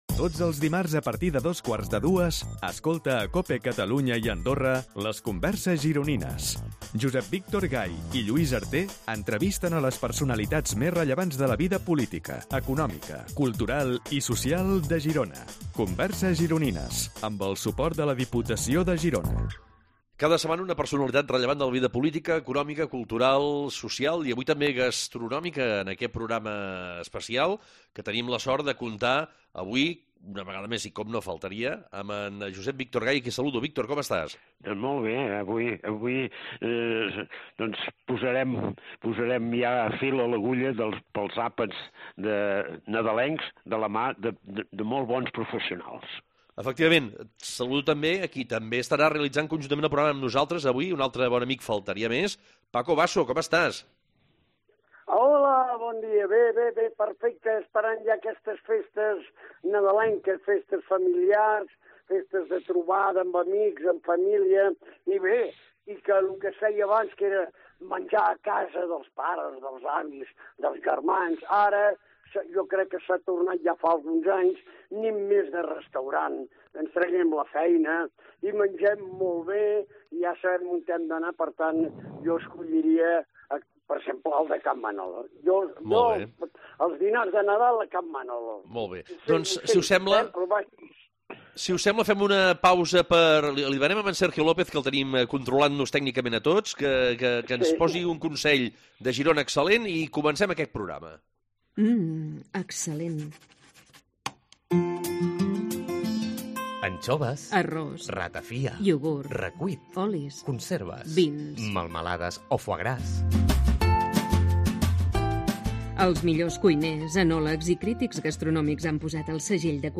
Aquestes converses es creen en un format de tertúlia en el que en un clima distès i relaxat els convidats ens sorprenen pels seus coneixements i pel relat de les seves trajectòries. Actualment el programa s’enregistra i emet en els estudis de la Cadena Cope a Girona, situats en el carrer de la Sèquia número tres de Girona, just al costat del museu del Cinema.